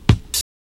WU_BD_271.wav